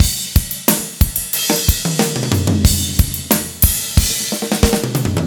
13 rhdrm91roll.wav